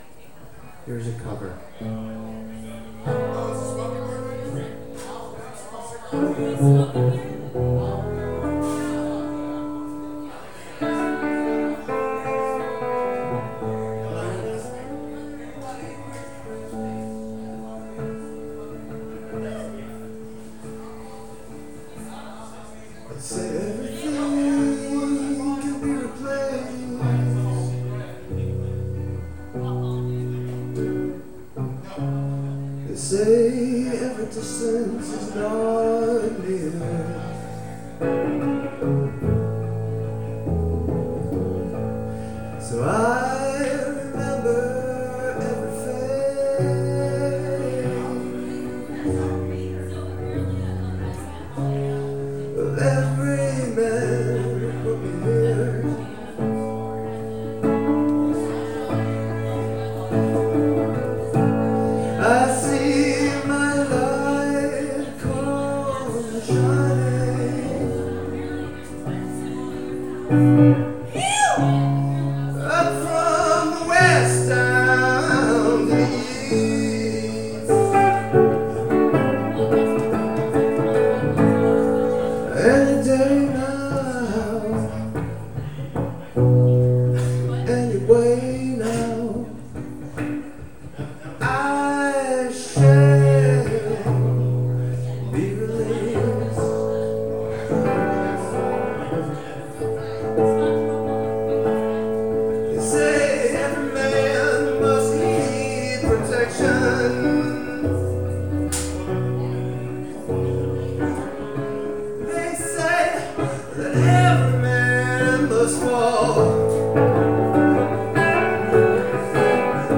Live MP3s